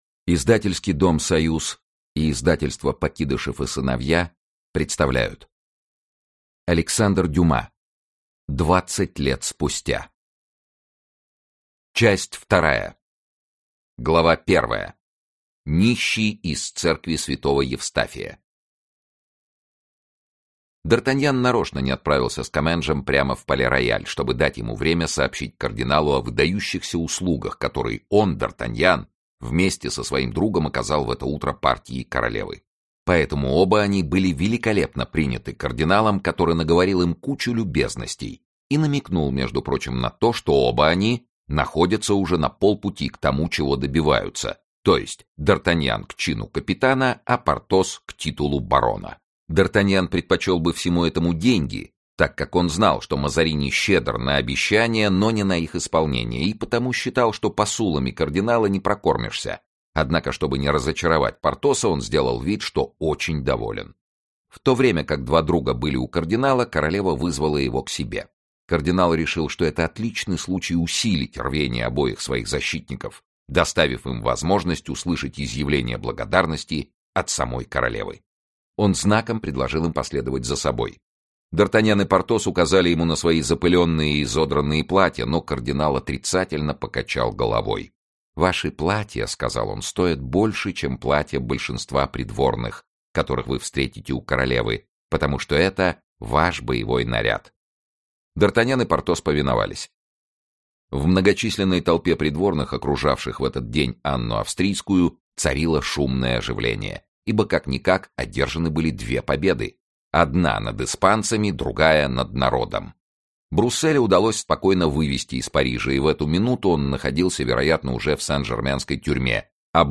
Аудиокнига Двадцать лет спустя. Часть 3 | Библиотека аудиокниг
Часть 3 Автор Александр Дюма Читает аудиокнигу Сергей Чонишвили.